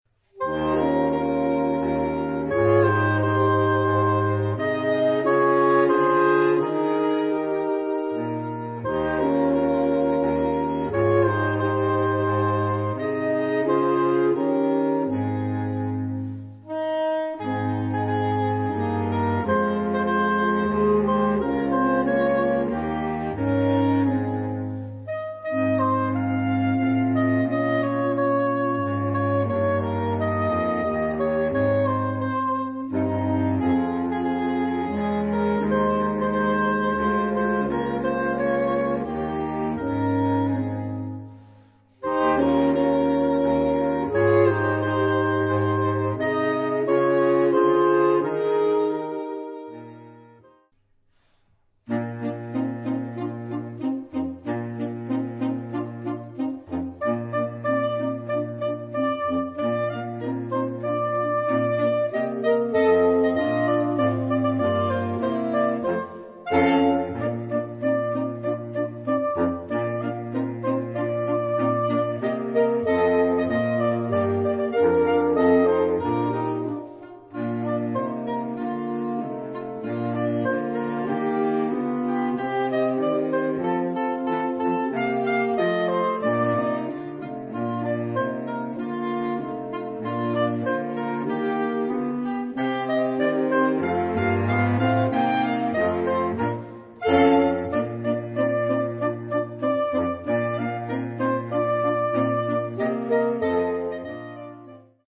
Gattung: für Holzbläserquartett